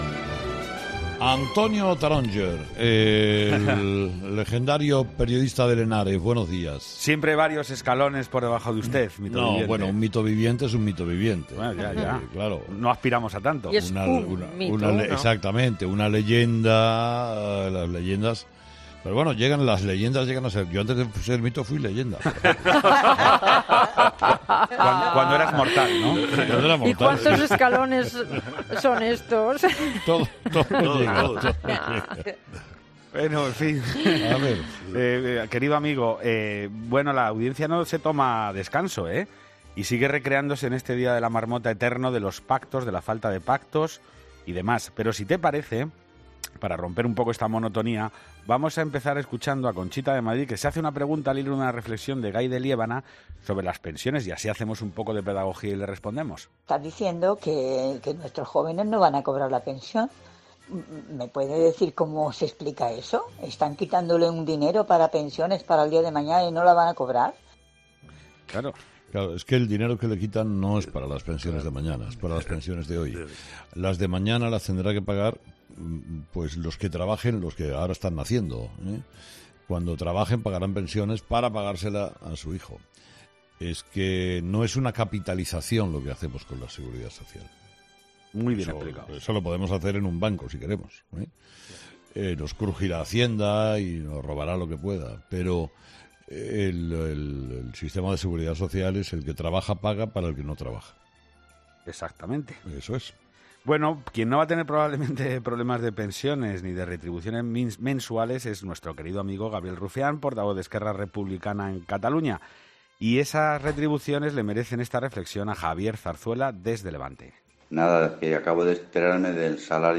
Y así, entre temas, transcurre el espacio en el que los oyentes dialogan con Herrera